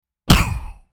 Download Pain sound effect for free.
Pain